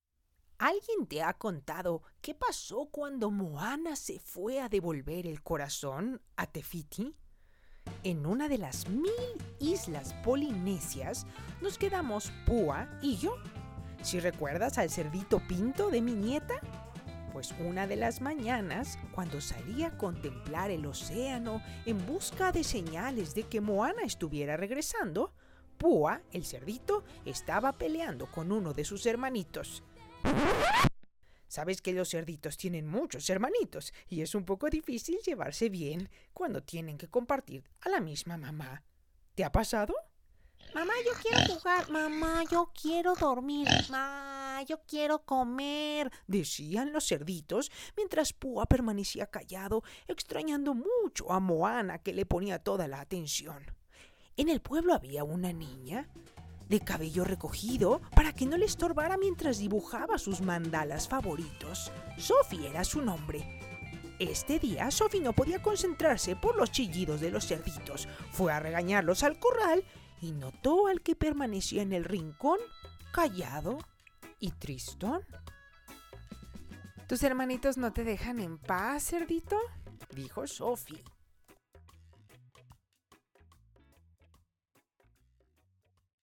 Muestra de Audiocuento Personalizado
Audiocuento grabado: Único, original, narrado y musicalizado.